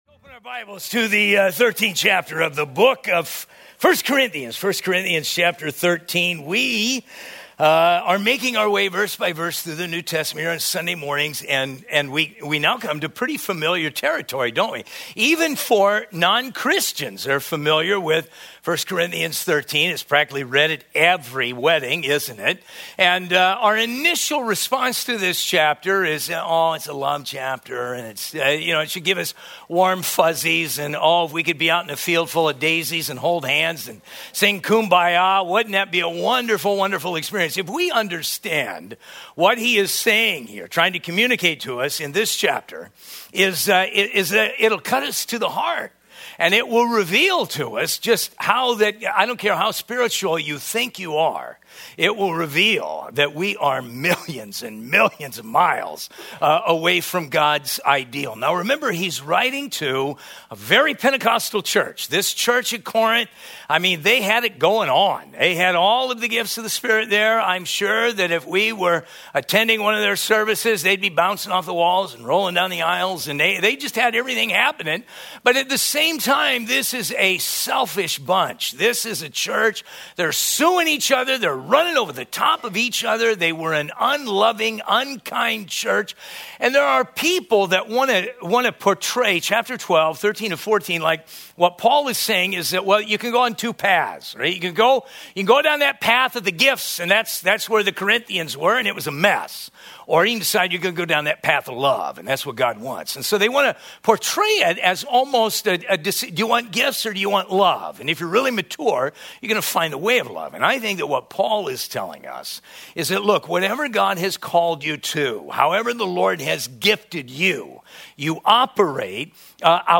A study in 1 Corinthians 13